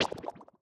Babushka / audio / sfx / Battle / Enemies / SFX_Slime_Hit_V2_06.wav
SFX_Slime_Hit_V2_06.wav